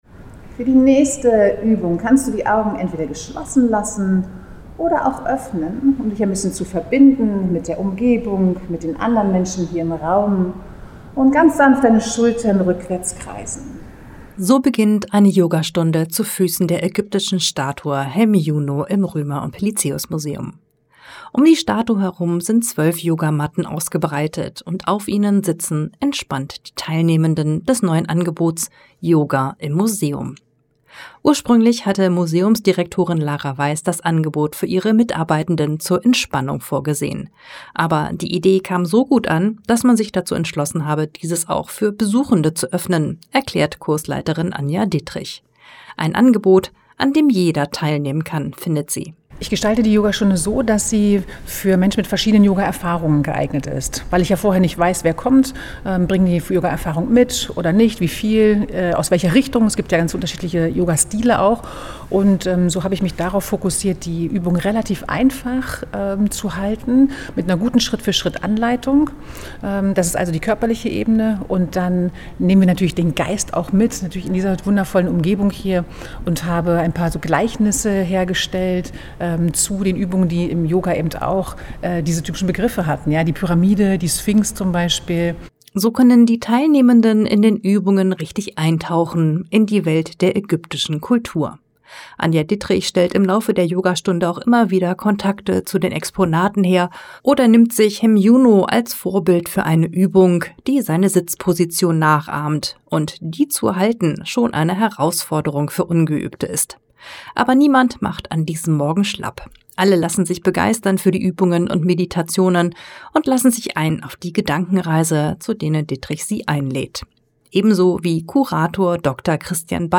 Hier bekommst Du einen Eindruck von unserer Auftaktveranstaltung, die YogaStunde an der Hemiunu-Statue.
Zum Radio-Beitrag